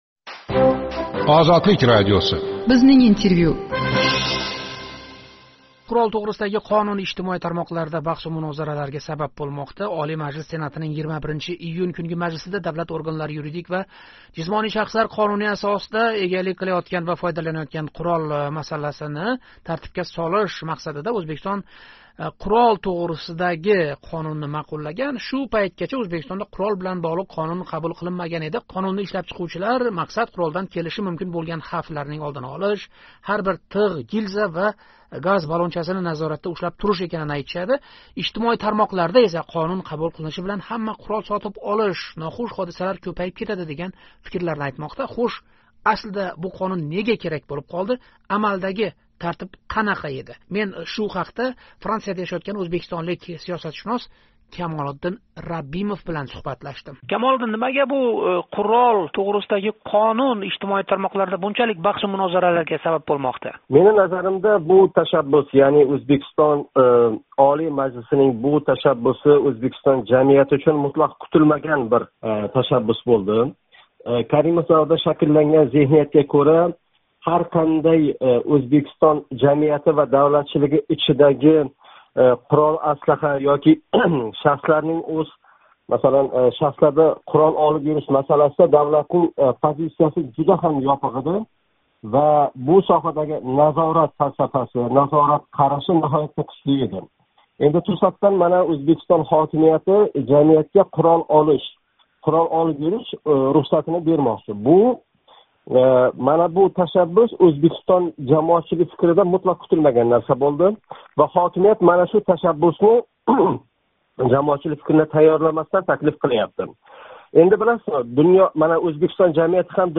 Бизнинг интервью